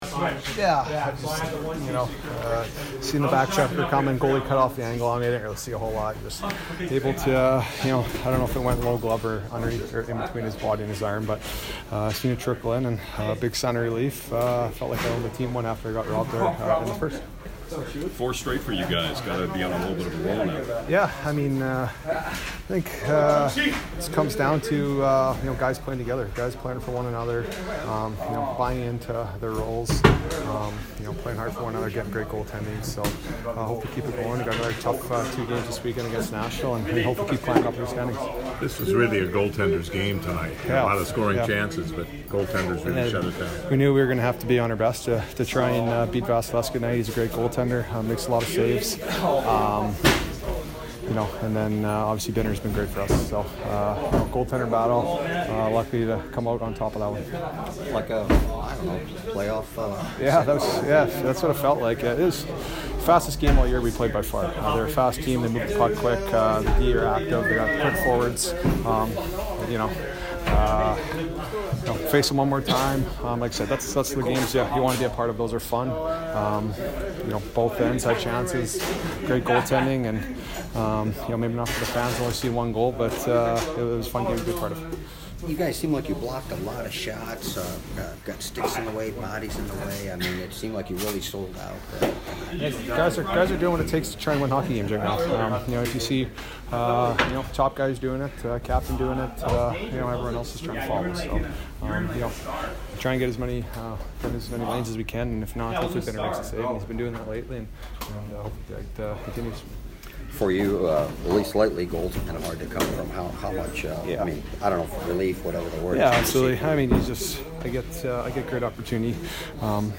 Brayden Schenn post-game 2/7